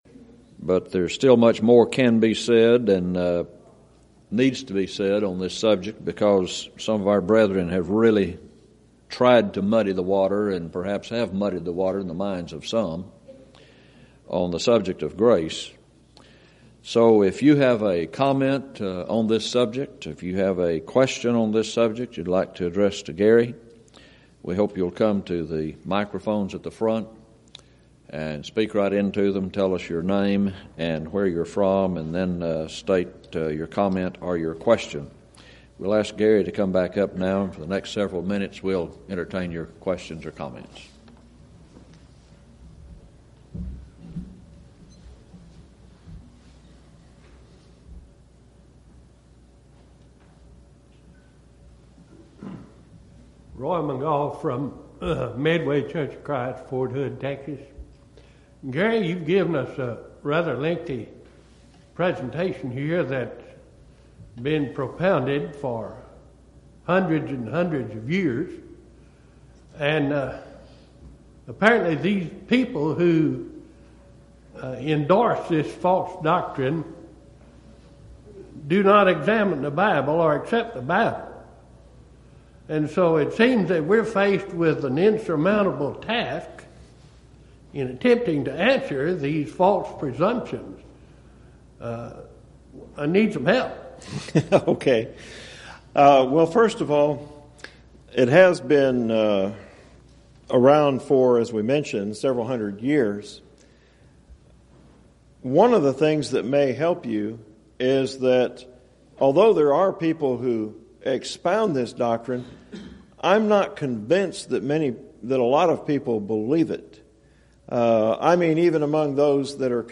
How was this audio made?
Title: Thursday Forum: What Does the Bible Teach About God's Grace? (Questions from Floor) Event: 2nd Annual Schertz Lectures